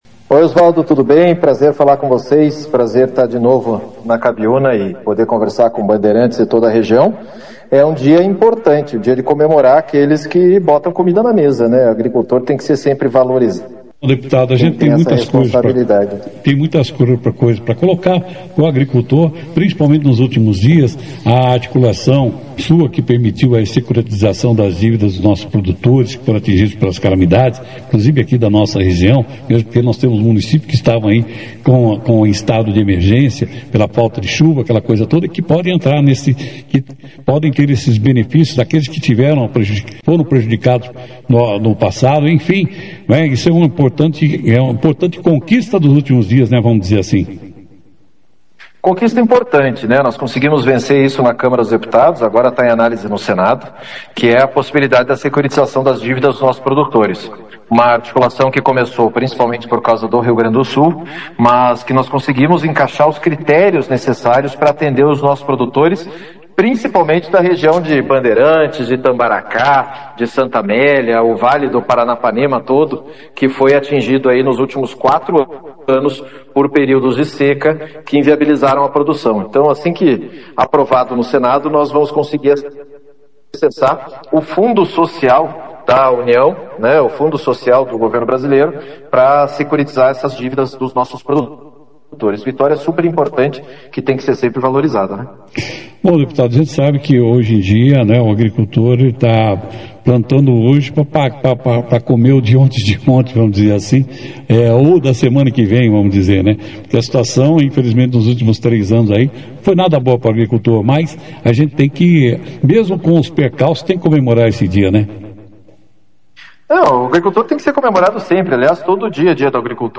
Nesta segunda-feira, 28 de julho, Dia do Agricultor, o deputado federal Pedro Lupion, presidente da Frente Parlamentar da Agropecuária (FPA) – a maior bancada do Congresso Nacional – participou ao vivo da 2ª edição do Jornal Operação Cidade.
Durante a entrevista, Lupion ressaltou a importância do homem do campo para a economia e a segurança alimentar do país.